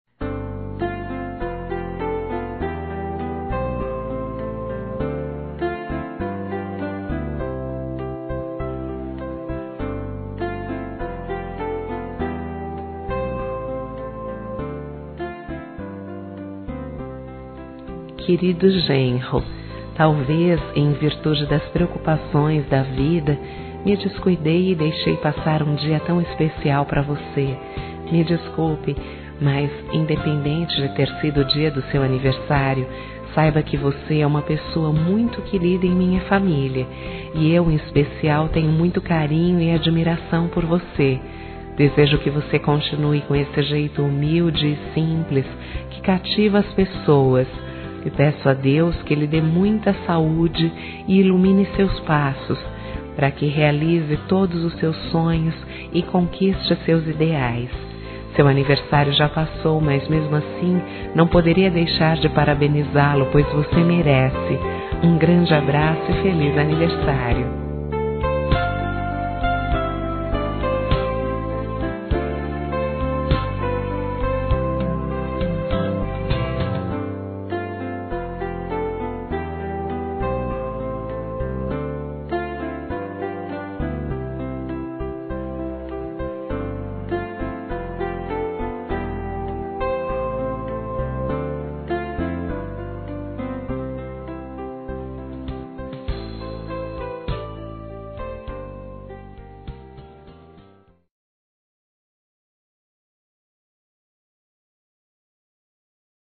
Aniversário de Genro – Voz Feminina – Cód: 2425 – Atrasado